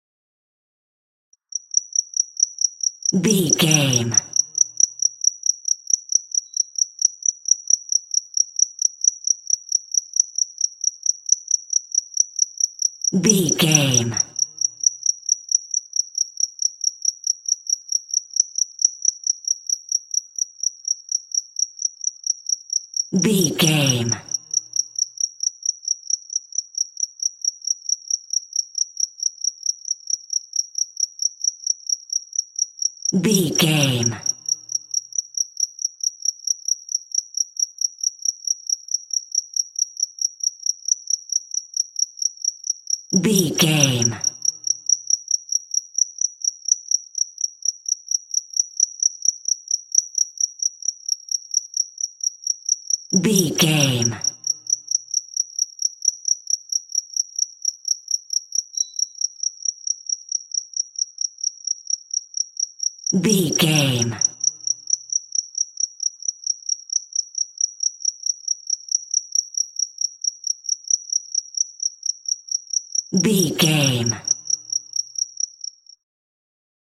City cricket
Sound Effects
nature
urban
ambience